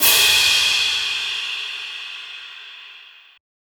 • Long Room Reverb Crash Sound E Key 01.wav
Royality free crash single shot tuned to the E note. Loudest frequency: 4936Hz
long-room-reverb-crash-sound-e-key-01-TbU.wav